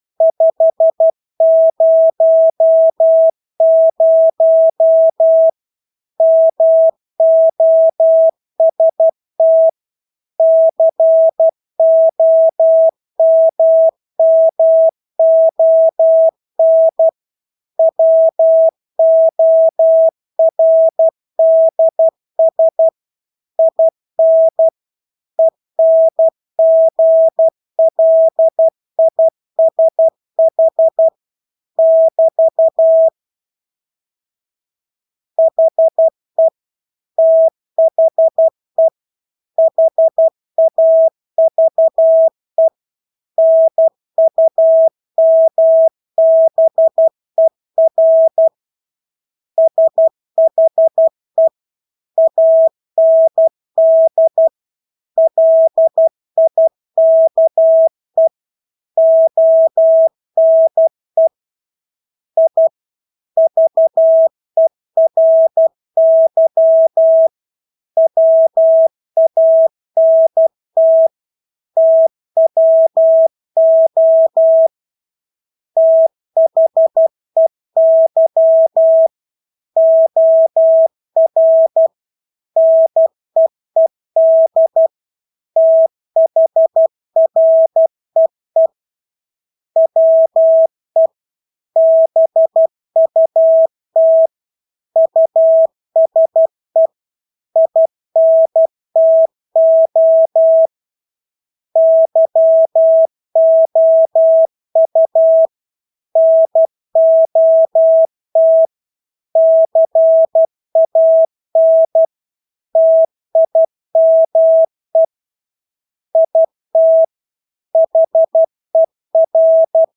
Engelske ord 12 wpm | CW med Gnister
Engelske ord 12-12 wpm.mp3